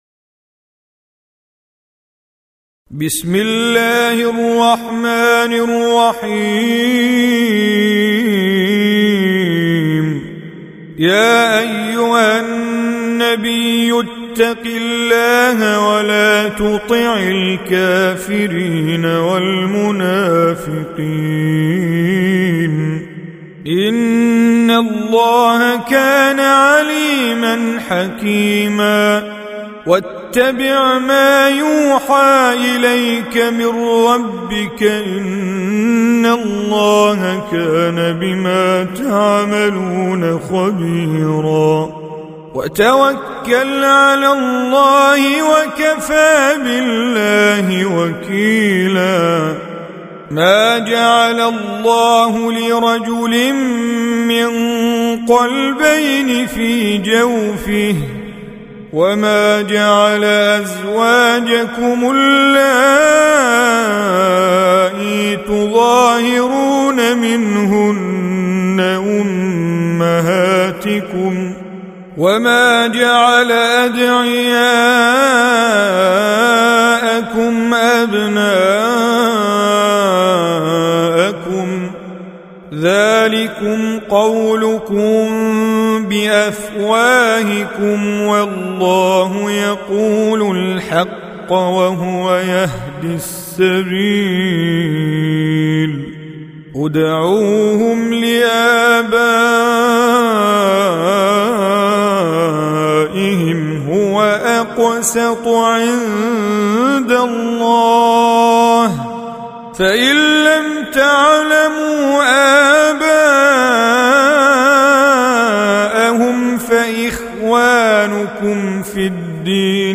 Surah Repeating تكرار السورة Download Surah حمّل السورة Reciting Mujawwadah Audio for 33. Surah Al�Ahz�b سورة الأحزاب N.B *Surah Includes Al-Basmalah Reciters Sequents تتابع التلاوات Reciters Repeats تكرار التلاوات